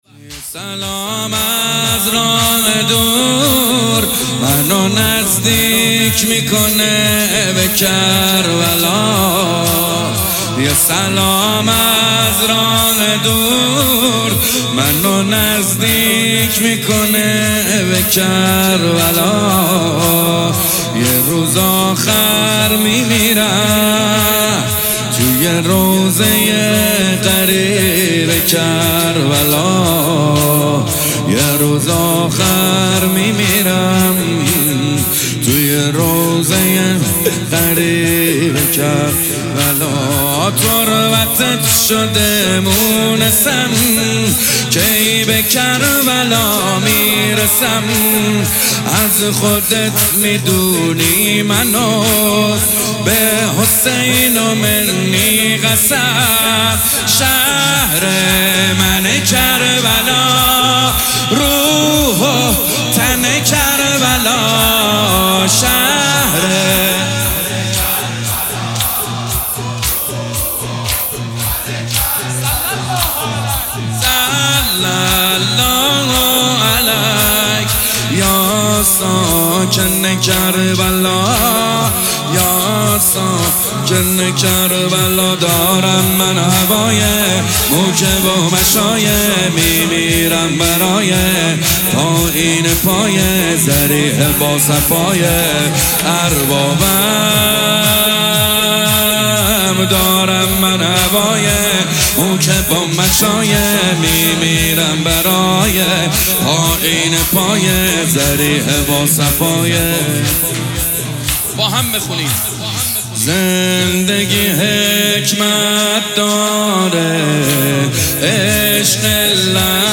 جلسه هفتگی 27 اردیبهشت 1404